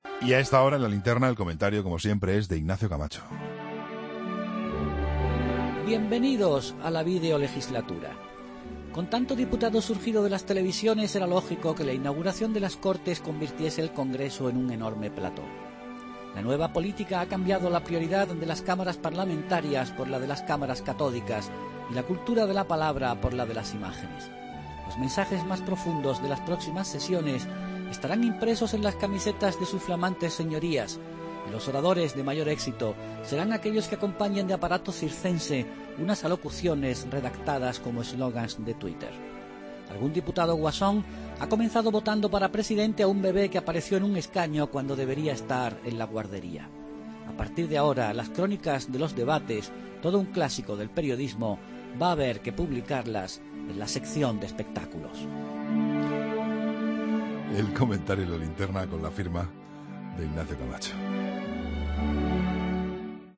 Ignacio Camacho dedica su comentario en La Linterna a la primera sesión plenaria tras las elecciones en el Congreso de los Diputados.